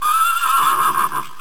Horse